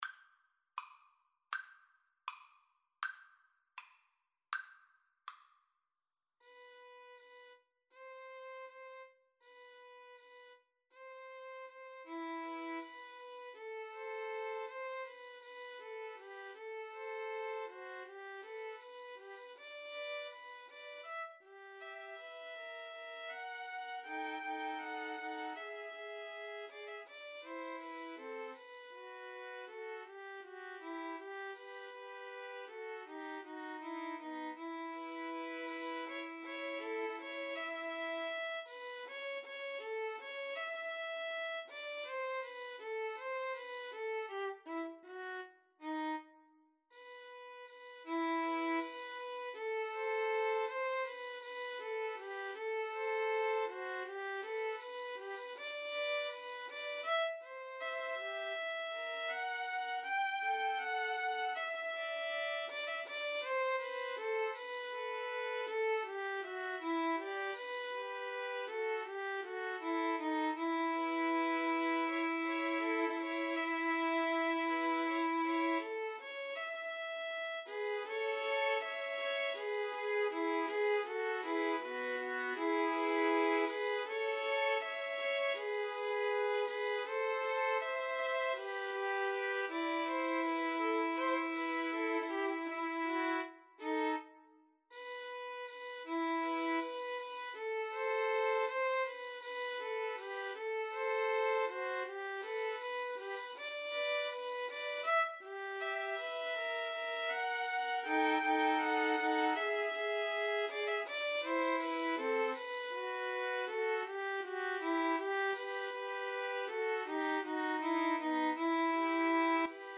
~ = 100 Andante
Violin Trio  (View more Intermediate Violin Trio Music)
Classical (View more Classical Violin Trio Music)